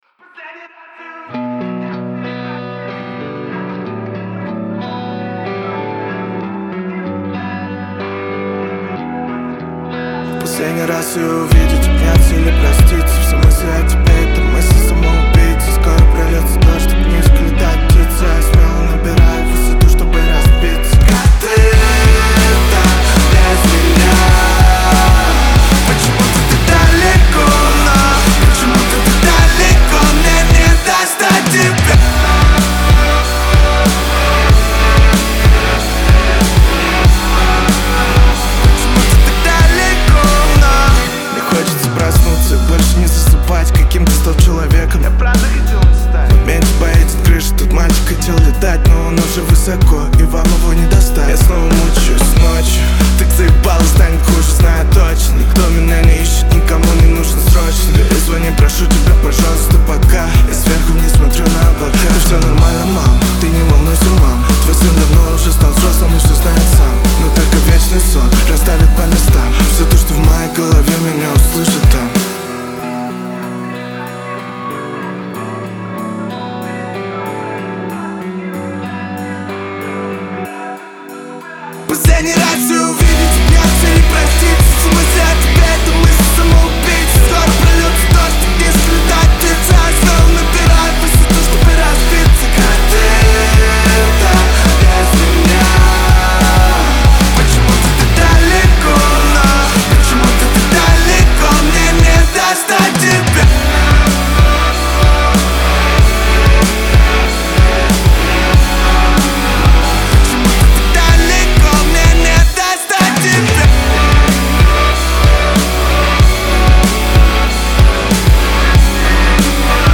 Трек размещён в разделе Русские песни / Альтернатива / 2022.